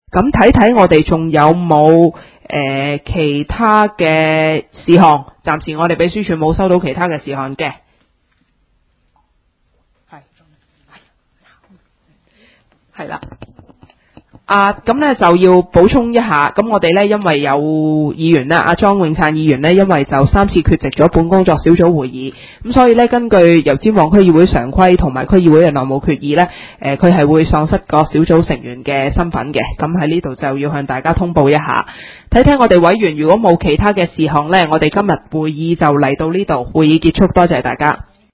工作小组会议的录音记录
妇女事务工作小组第九次会议 日期: 2015-04-02 (星期四) 时间: 上午10时30分 地点: 九龙旺角联运街30号 旺角政府合署4楼 油尖旺区议会会议室 议程 讨论时间 1.